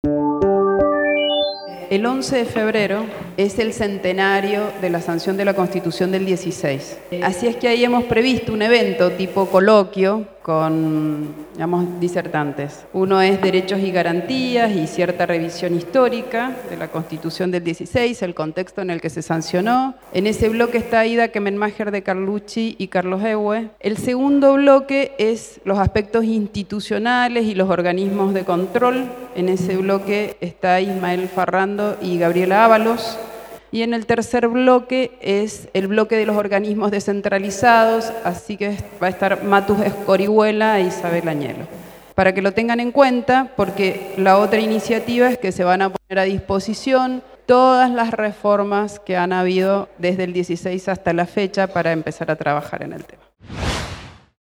Audio de la vicegobernadora Laura Montero sobre la realización de la actividad.